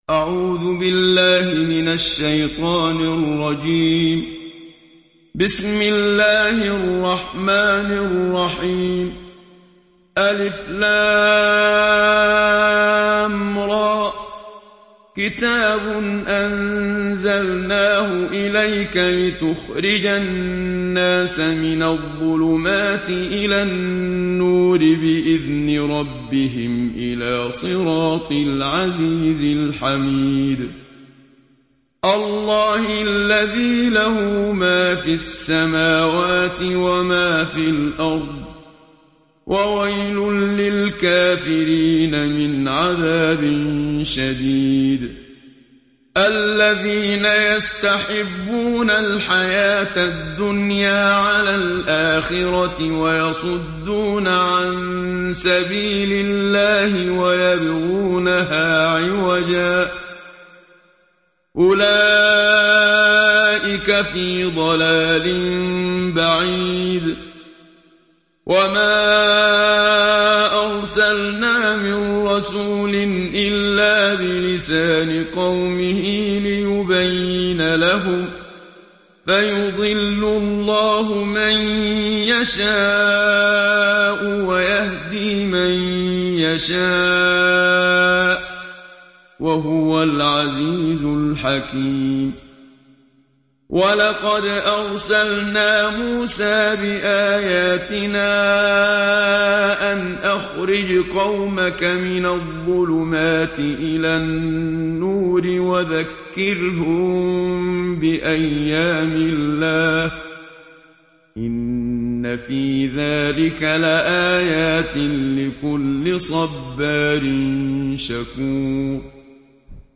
Mojawad